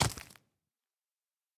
minecraft / sounds / mob / zombie / step5.ogg
step5.ogg